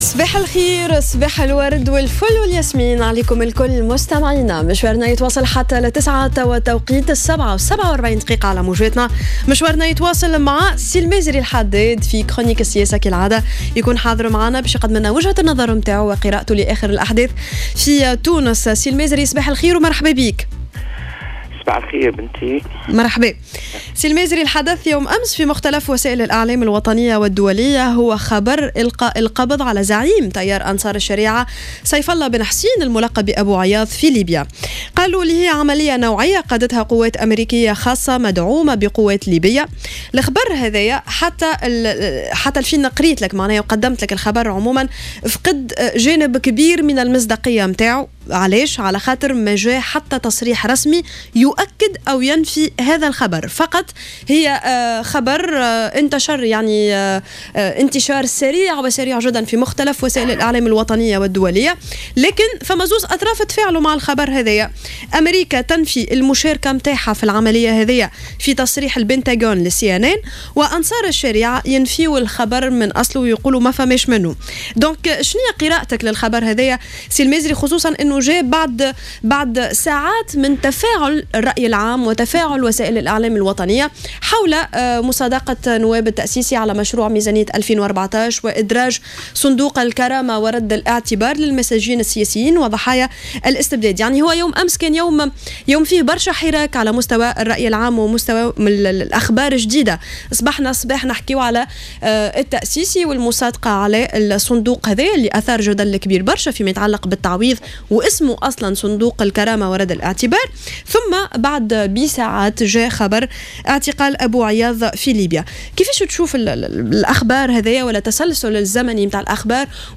توقّع السفير السابق لدى اليونسكو،المازري الحداد في برنامج "صباح الورد" على" جوهرة أف أم" وقوع عمليات انتقامية في تونس وليبيا بعد اعتقال أبو عياض.